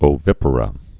(ō-vĭpər-ə)